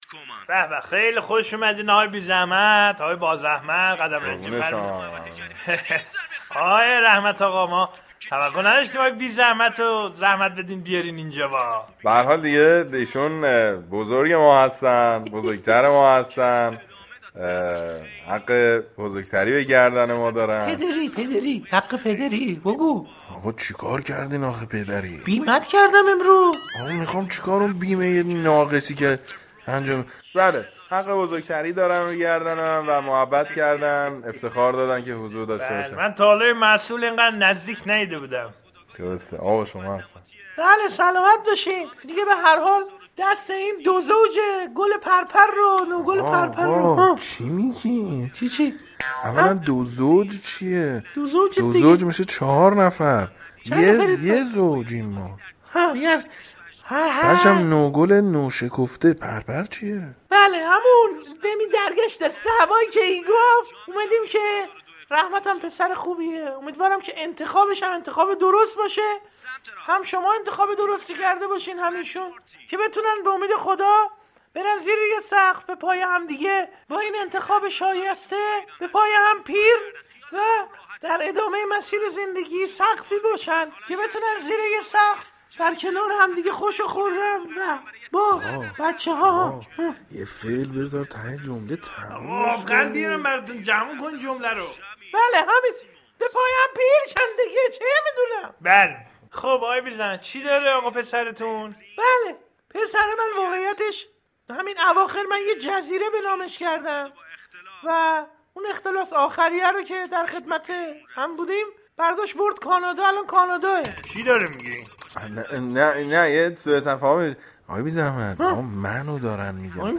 برنامه طنز «بی زحمت» با حضور شخصیت های مسئول، راننده و خبرنگار در قالب نمایش رادیویی به نقد این مسئله می پردازد و تلاش می كند این موضوع را طنازانه به اطلاع مسئولان می رساند.